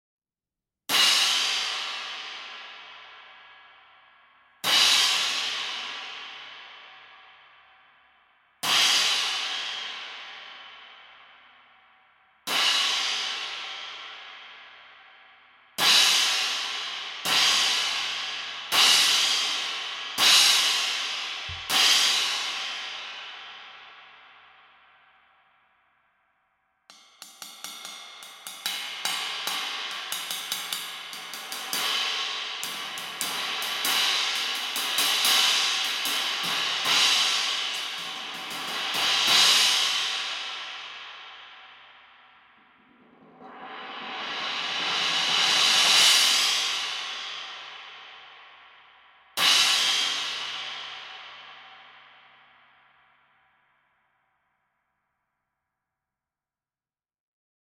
20″ Armor Holey Crash with multi hole pattern (Approx 1300 grams):
20__Armor-Holey-Crash.mp3